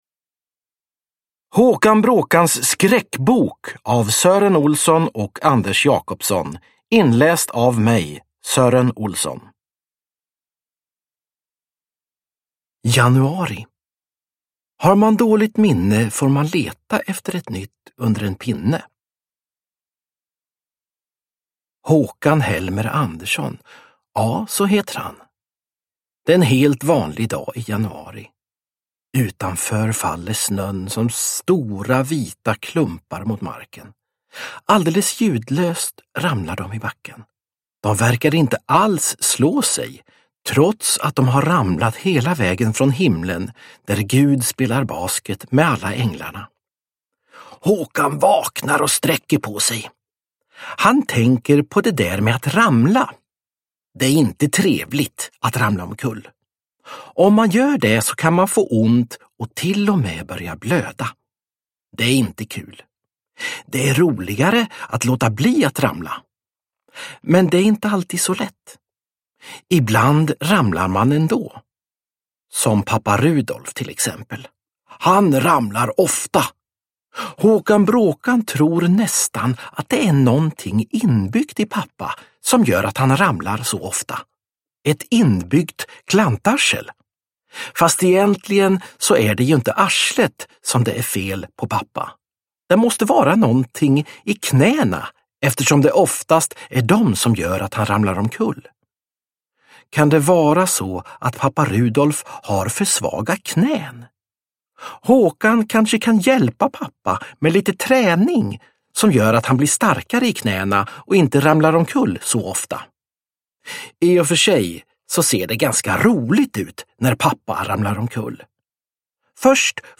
Håkan Bråkans skräckbok – Ljudbok – Laddas ner